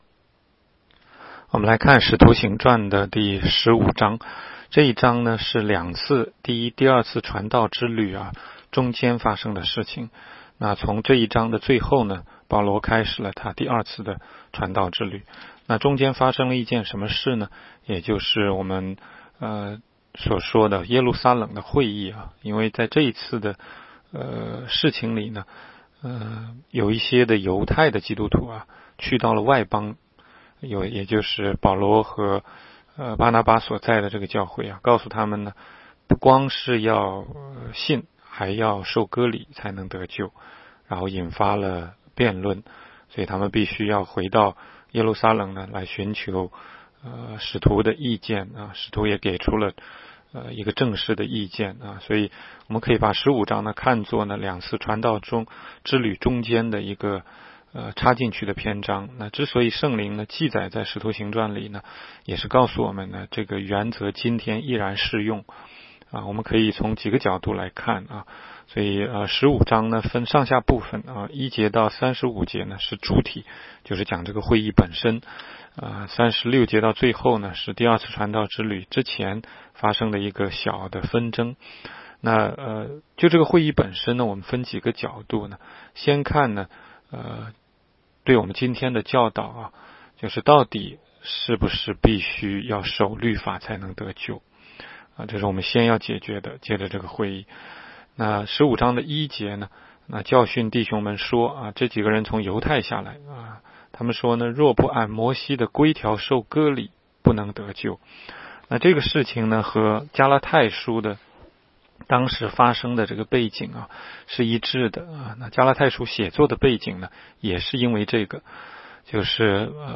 16街讲道录音 - 每日读经-《使徒行传》15章